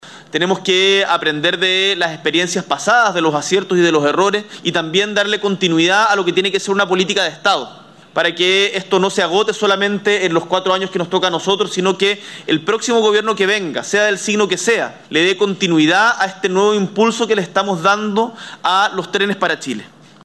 Desde la estación de ferrocarriles de la comuna de Rengo, el presidente de la República, el ministro de Transportes y el presidente de Ferrocarriles del Estado (EFE) comprometieron una serie de mejoras al transporte ferroviario que presta servicios en las regiones de Ñuble, Maule y O’Higgins, los que consideran la modernización de la maquinaria, la habilitación de nuevas detenciones y la reducción de los tiempos de espera.
En ese sentido, el mandatario afirmó que estos proyectos beneficiarán a una población estimada de 90 mil personas.
01-Gabriel-Boric.mp3